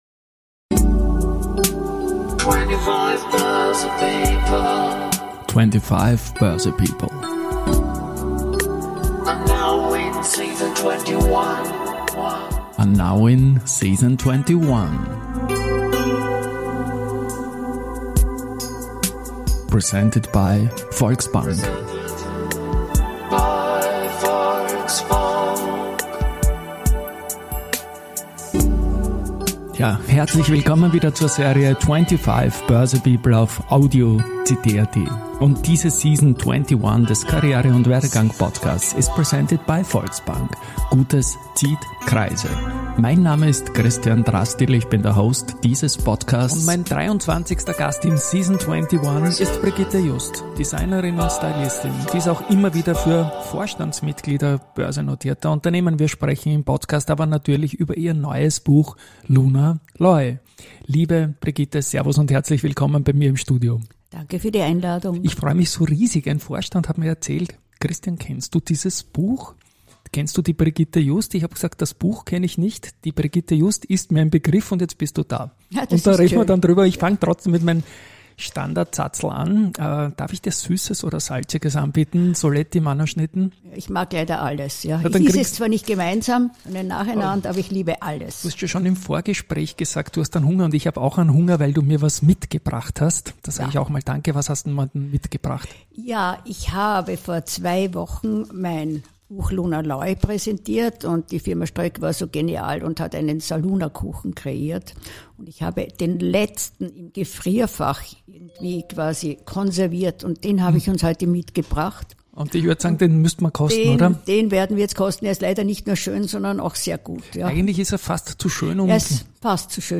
Ab Minute 15 ist der Talk mit der Original-Musik vom Hörbuch unterlegt.
Es handelt sich dabei um typische Personality- und Werdegang-Gespräche.